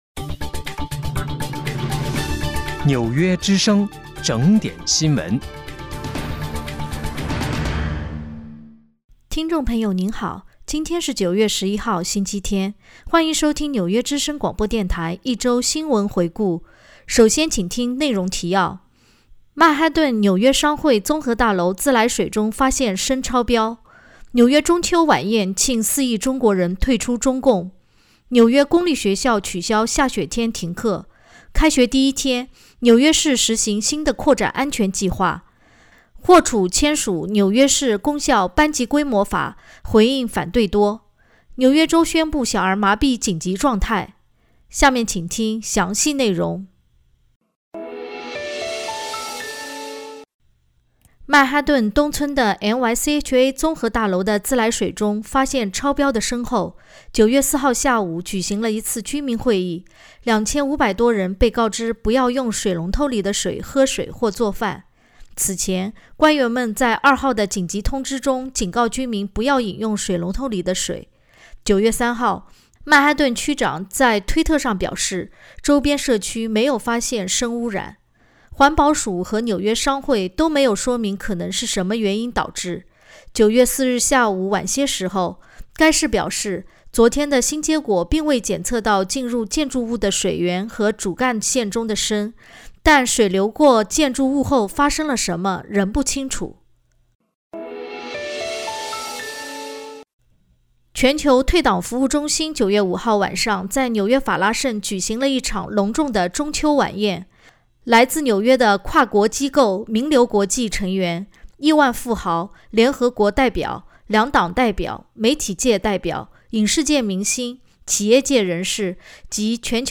9月11日（星期日）一周新闻回顾